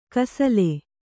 ♪ kasale